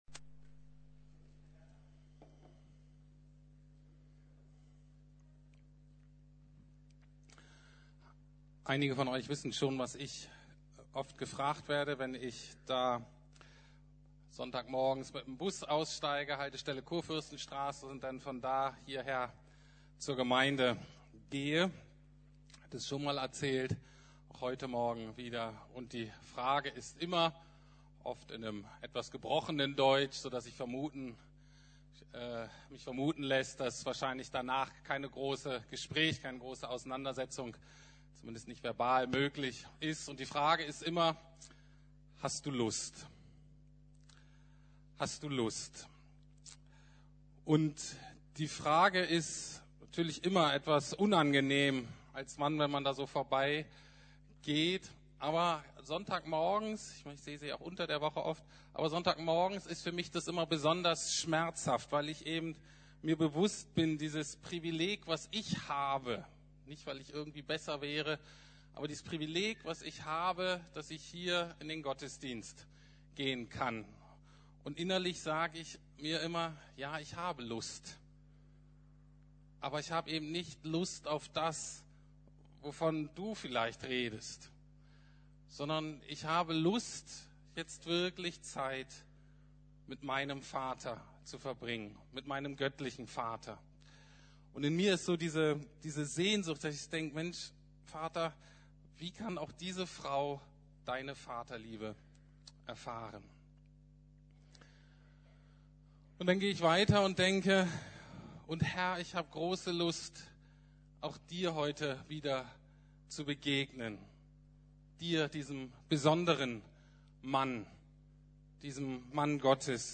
Gottesdienst gegen sexuelle Ausbeutung